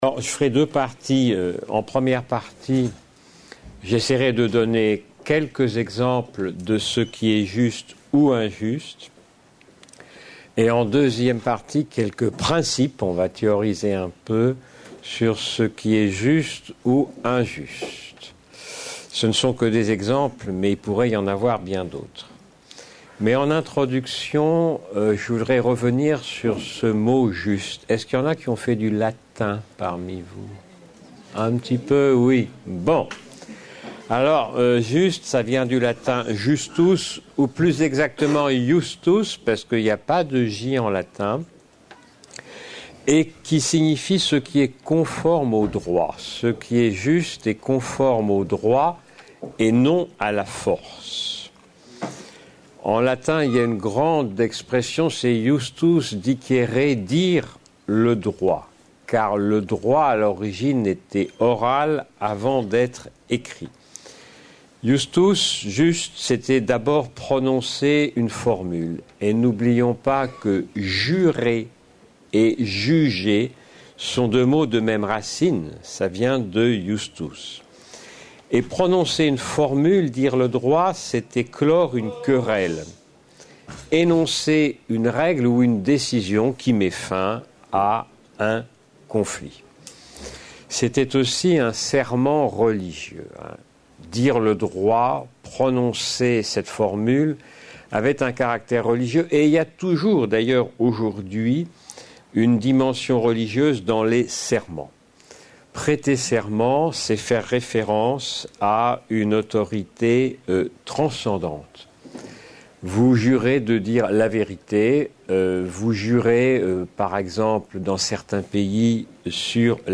Une conférence de l'UTLS au Lycée La justice : qu'est ce qui est juste ? par Odon Vallet Lycée Sainte Clotilde ( 93 Le Raincy)